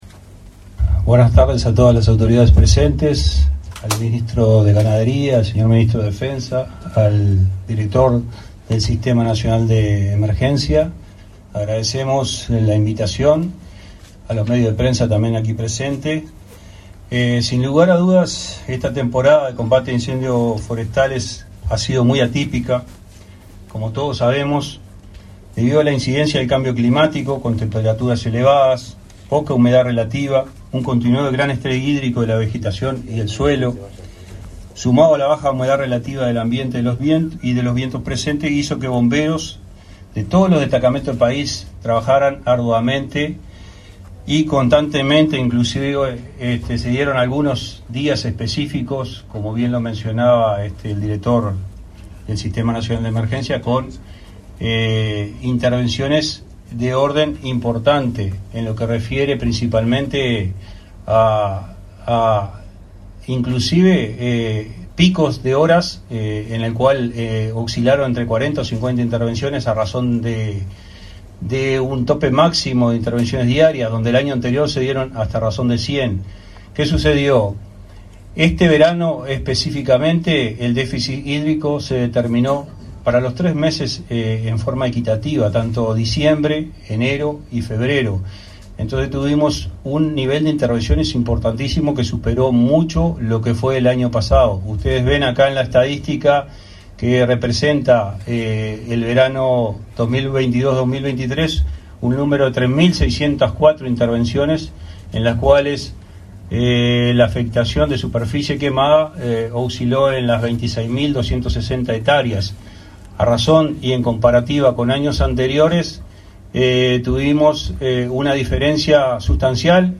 Conferencia de prensa por balance de acciones contra los incendios
Conferencia de prensa por balance de acciones contra los incendios 27/04/2023 Compartir Facebook Twitter Copiar enlace WhatsApp LinkedIn Este 27 de abril se realizó una conferencia conjunta entre los ministros de Ganadería, Agricultura y Pesca, Fernando Mattos, Defensa Nacional, Javier García; el director nacional de Bomberos, Ricardo Riaño, y el director nacional de Emergencias del Sinae, Sergio Rico.